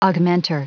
Prononciation du mot augmentor en anglais (fichier audio)
Prononciation du mot : augmentor